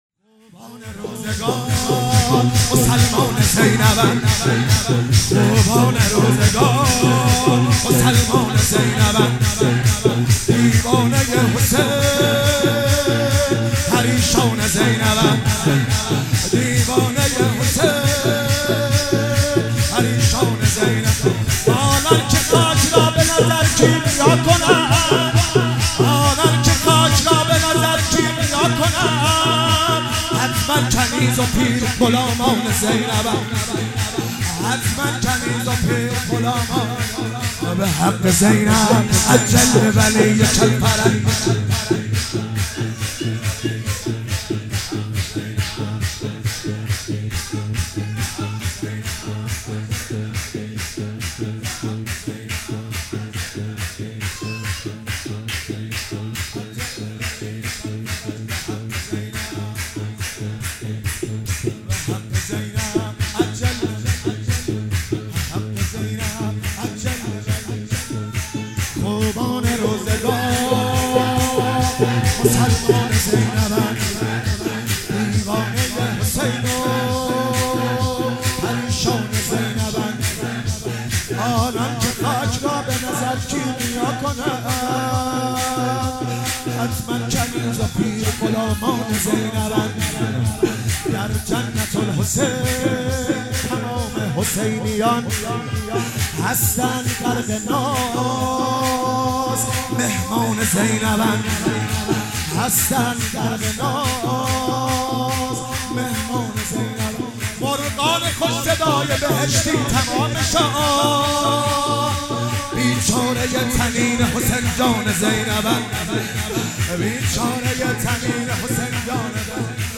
ولادت حضرت زینب سلام الله علیها 96 - شور - خوبان روزگار مسلمان زینب اند